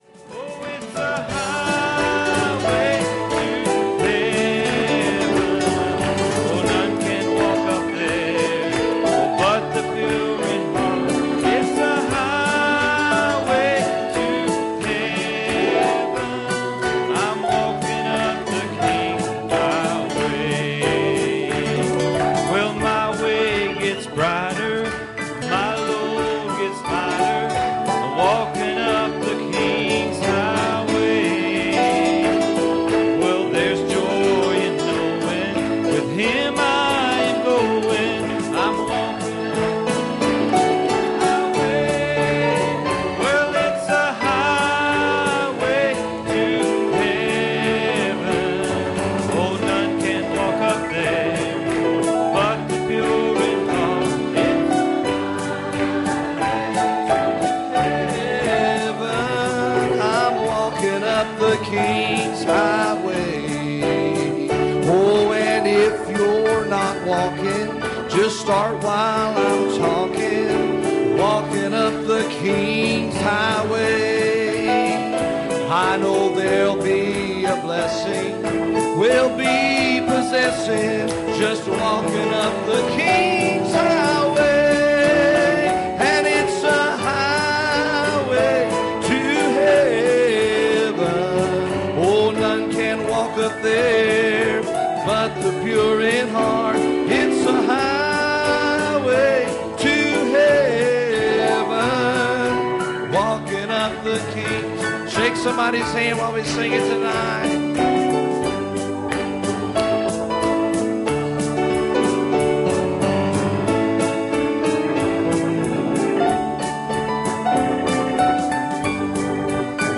Passage: Matthew 13:24 Service Type: Sunday Evening